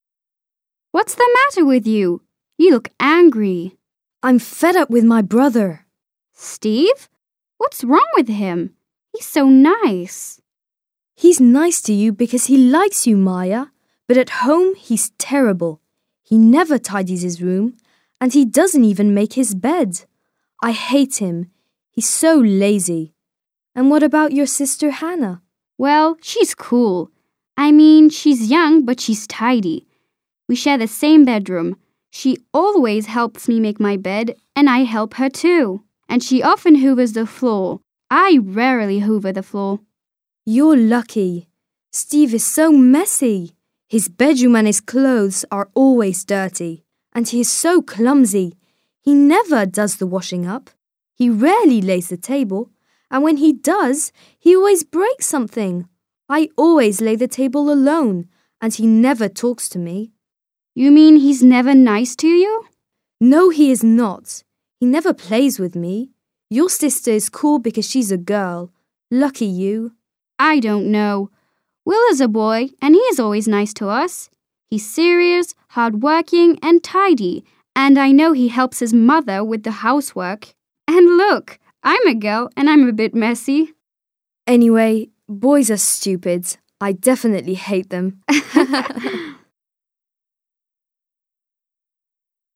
Put a tick (✔) when the character does the activity and a cross (✖) when he or she doesn’t. Listen to Maya talking to Jenny.